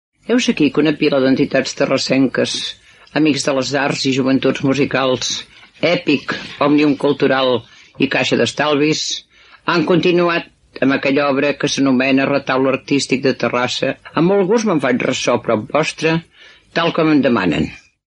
Comentari sobre un retaule artístic - Ràdio Terrassa
Àudios: arxiu històric de Ràdio Terrassa